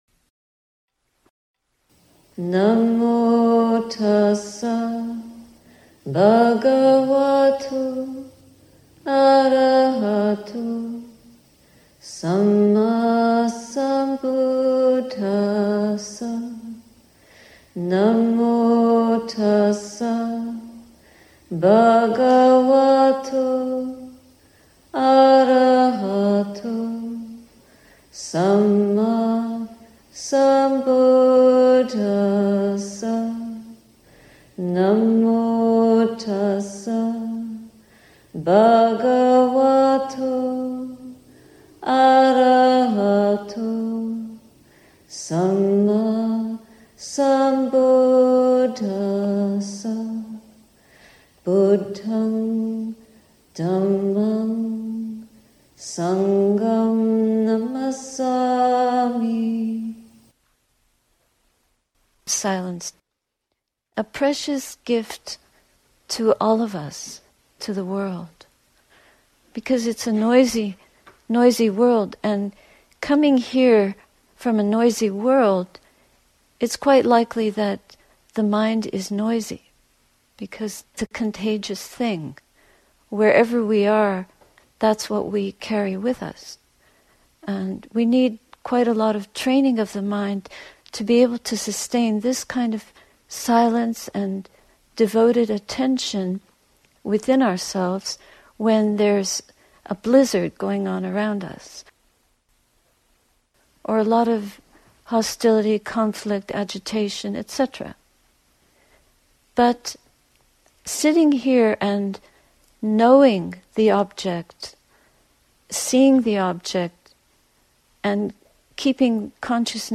A talk at the Heart of Wisdom Retreat, Insight Meditation Society, Massachusetts, USA in 2019.